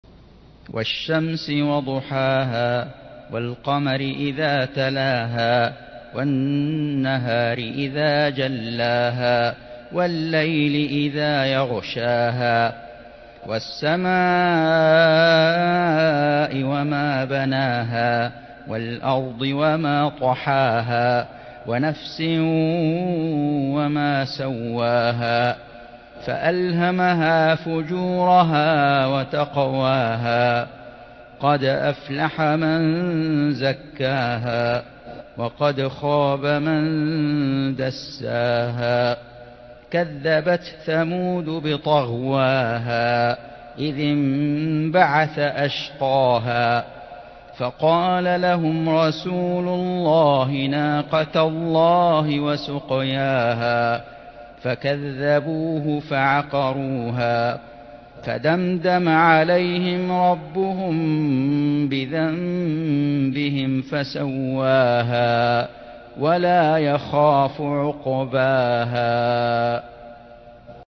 سورة الشمس > السور المكتملة للشيخ فيصل غزاوي من الحرم المكي 🕋 > السور المكتملة 🕋 > المزيد - تلاوات الحرمين